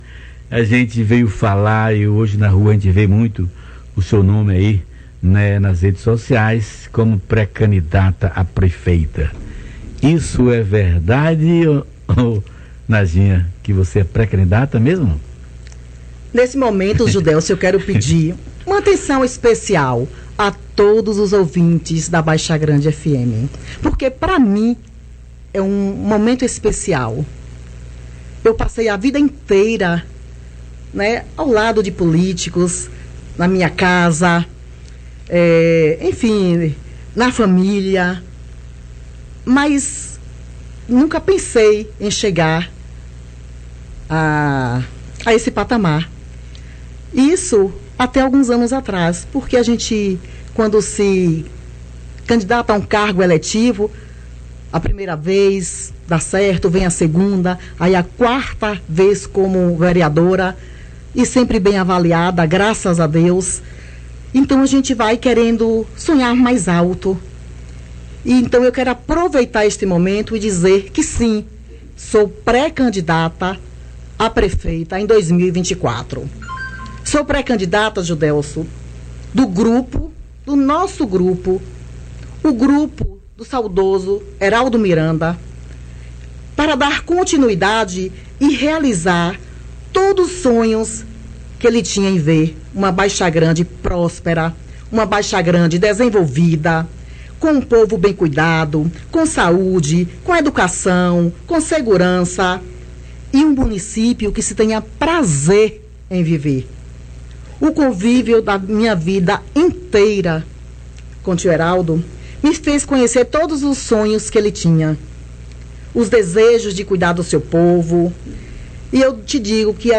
A vereadora Nadja Nara (Najinha), em seu quarto mandato consecutivo no Legisltivo, anunciou em um programa de rádio nesta sexta-feira, 09 de junho de 2023, sua pré-candidatura à prefeita de Baixa Grande nas eleições de 2024.
Clique no Play para ouvir o anúncio feito por Najinha